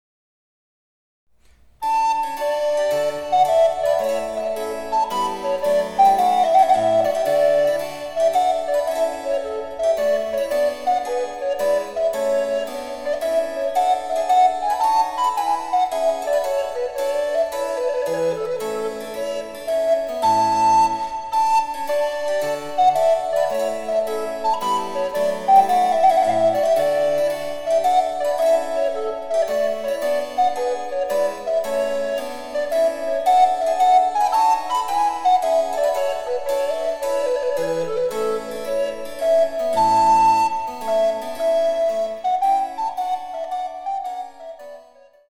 弾むように元気に演奏したい。
■リコーダーによる演奏